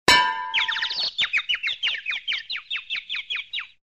Птицы кружат над головой после удара